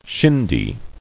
[shin·dy]